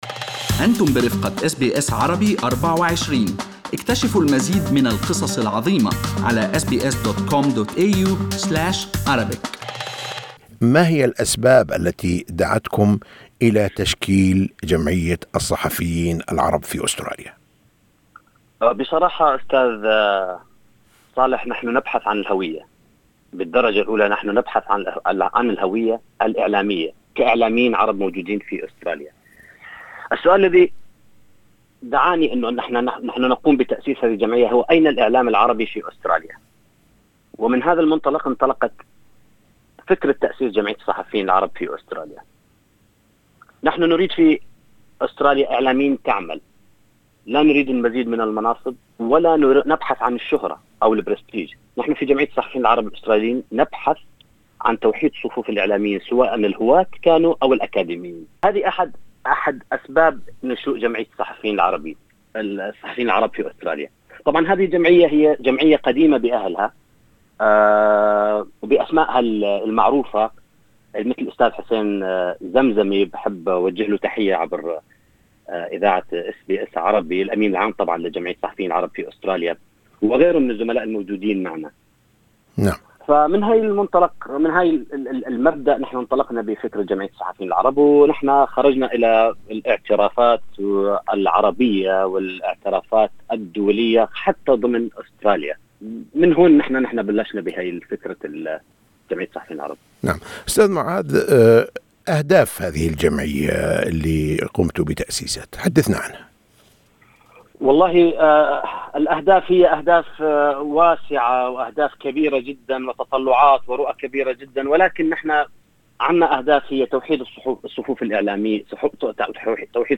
اللقاء